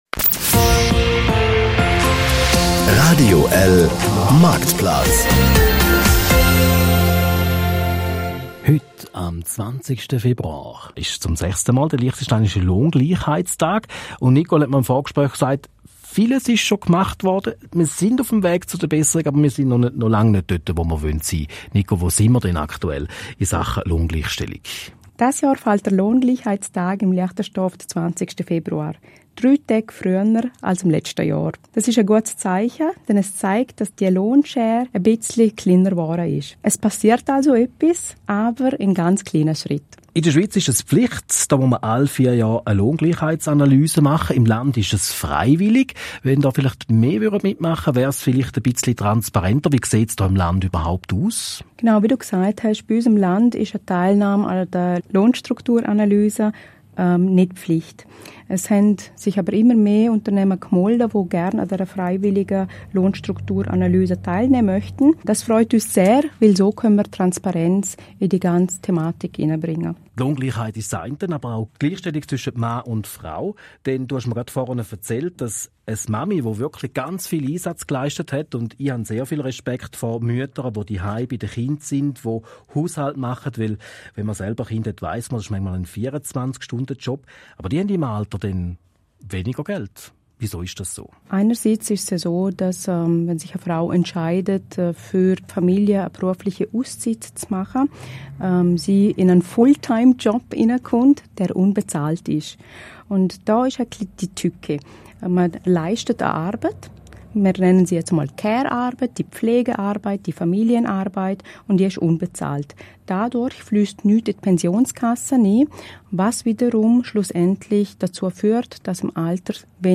Hier hören Sie den 2. Teil des Radiobeitrages vom 20.02.2023